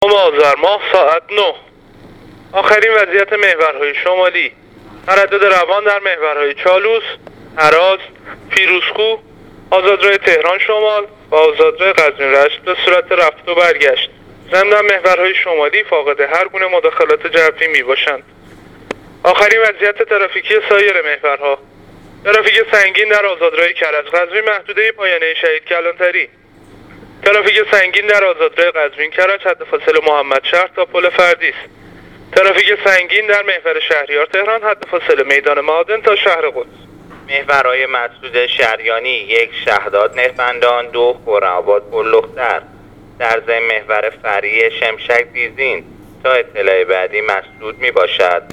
گزارش رادیو اینترنتی از آخرین وضعیت ترافیکی جاده‌ها تا ساعت ۹ نهم آذر؛